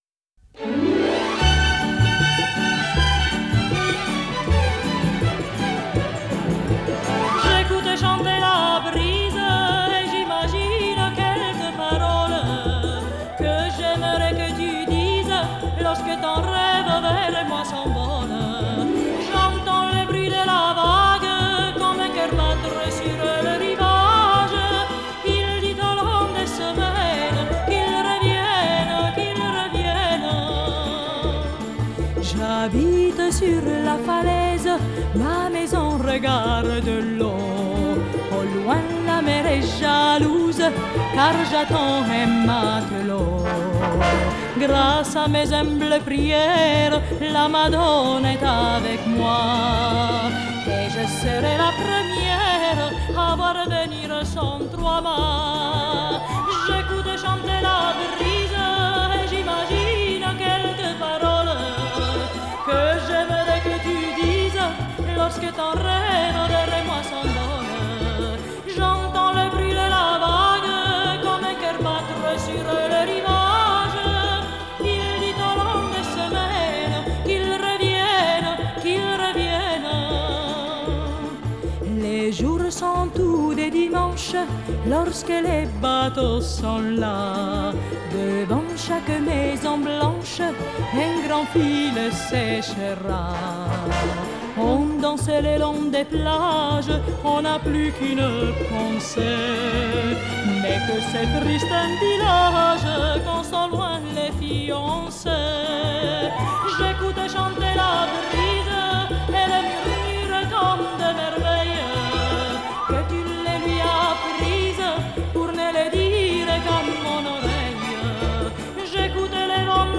Genre: Variete Francaise, Pop Vocale